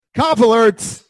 COP ALERTS! - Bouton sonore
COP ALERTS!
copalert.mp3